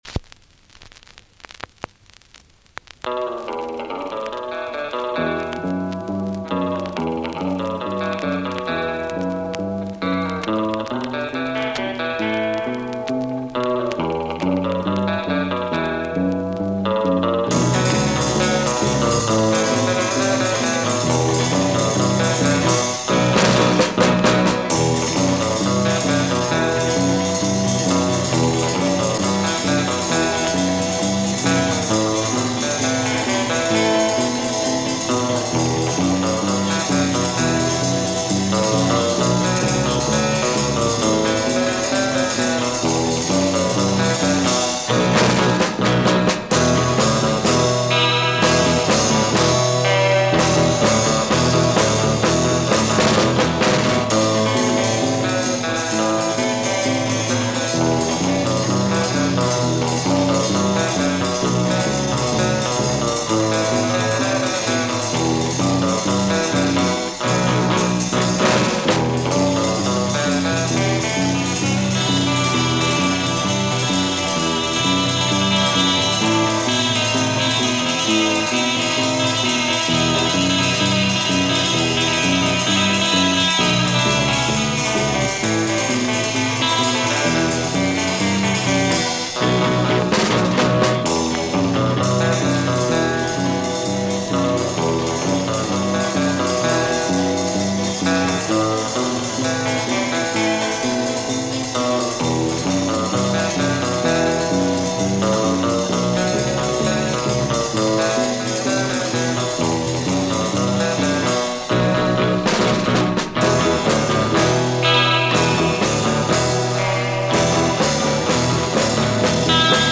Soyez indulgents, c'est un disque qui a beaucoup vécu !!!!